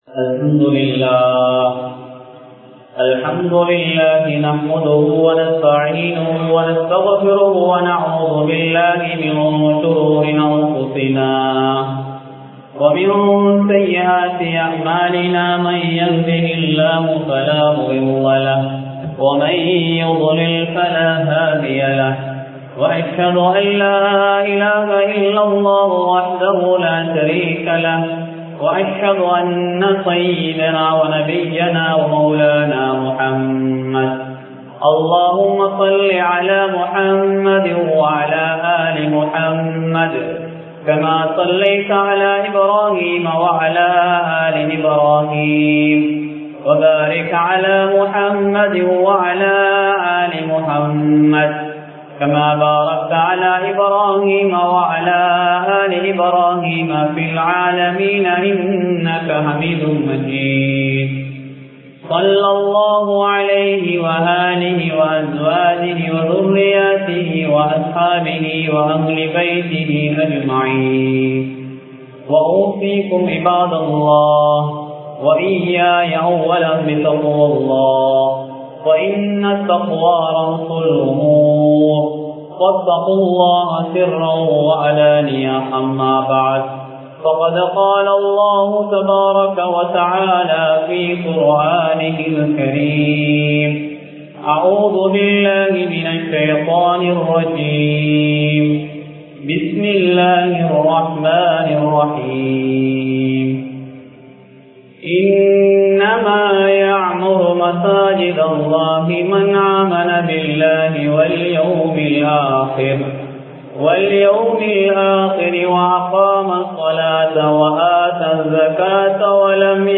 மஸ்ஜித் நிருவாகிகளின் பண்புகள் (The Qualities of Masjid Trustees) | Audio Bayans | All Ceylon Muslim Youth Community | Addalaichenai
Pannawa Jumuah Masjith